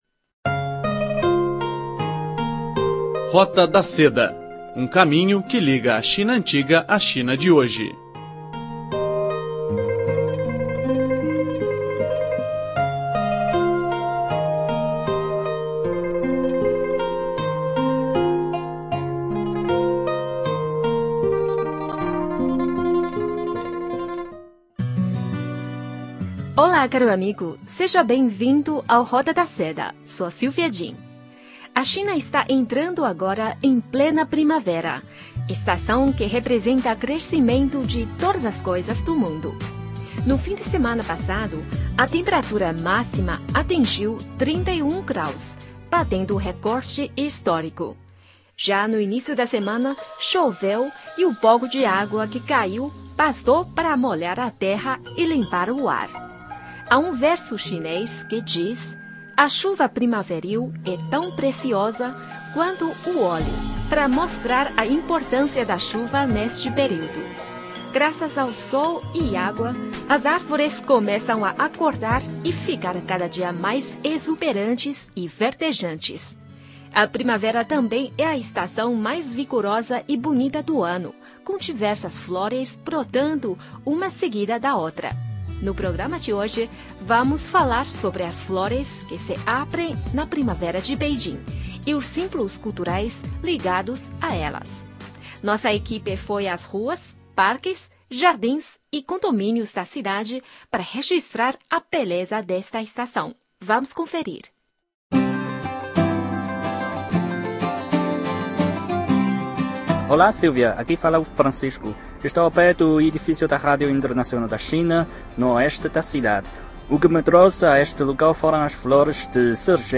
No programa de hoje, vamos falar sobre as flores que se abrem na primavera de Beijing e os símbolos culturais ligados a elas. Nossa equipe foi às ruas, parques, jardins e condomínios da cidade para registrar a beleza desta estação.